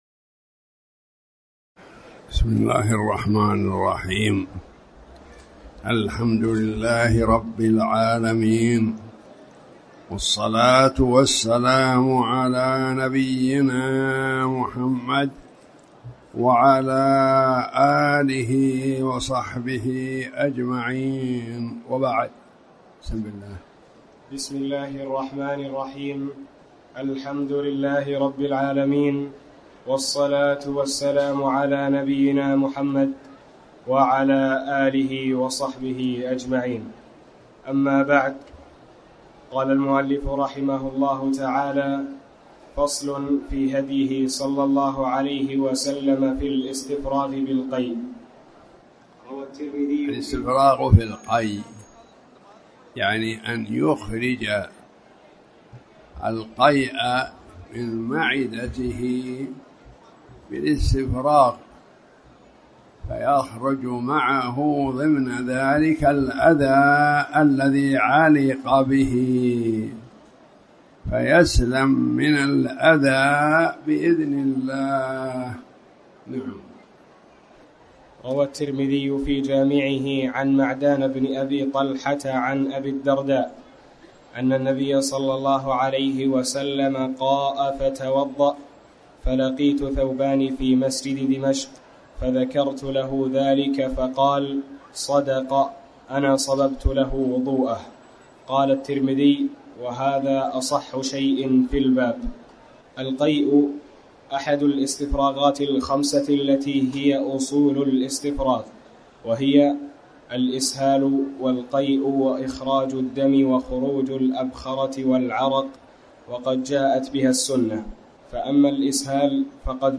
تاريخ النشر ٢٧ رجب ١٤٣٩ هـ المكان: المسجد الحرام الشيخ